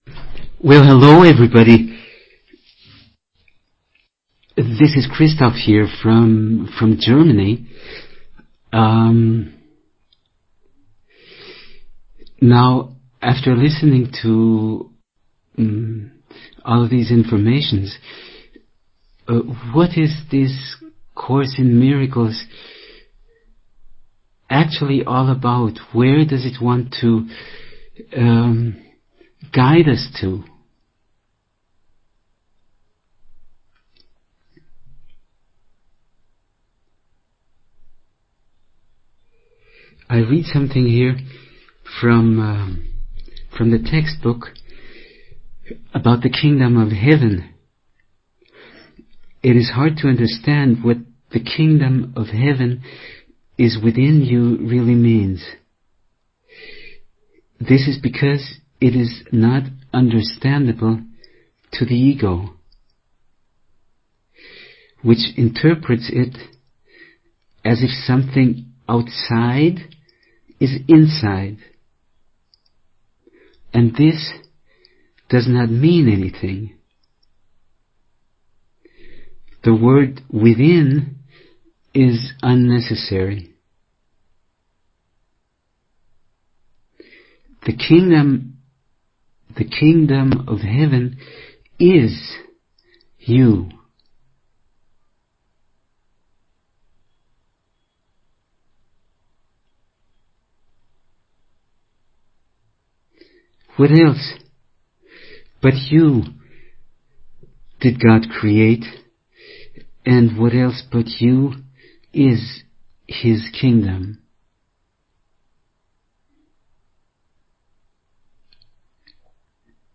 New Year recording from Awakening Together Radio